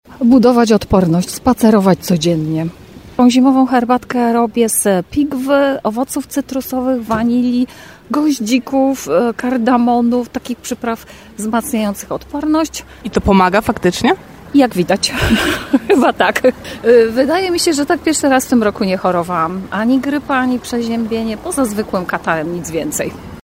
25Grypa1_sonda.mp3